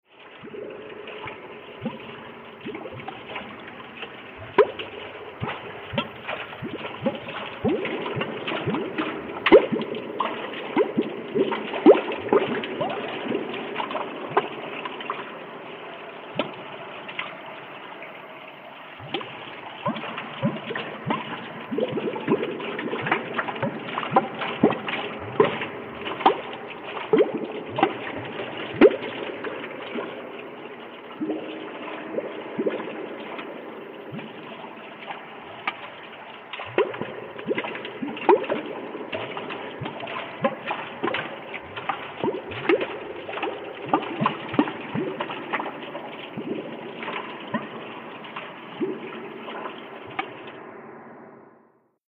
WATER-UNDERWATER FX SCUBA: Bottom of the ocean, deep underwater, bubbles, reverb.